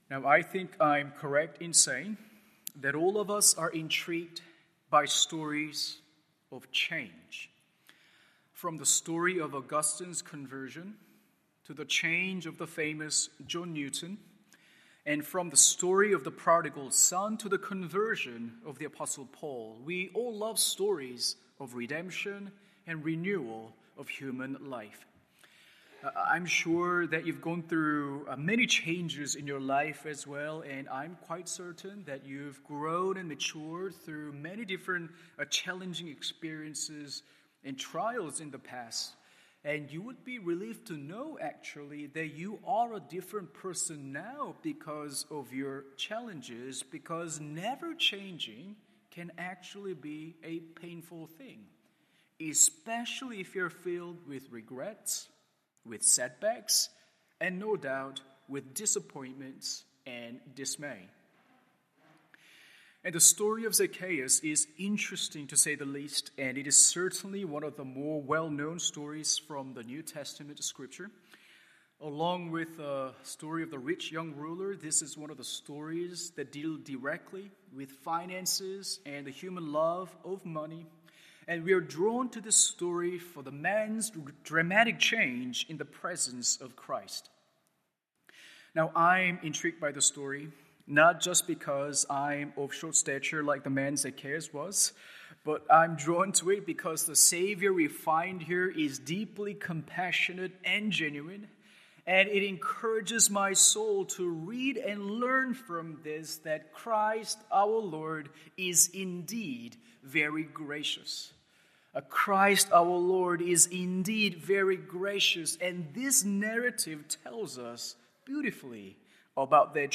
Morning Service Luke 19:1-10…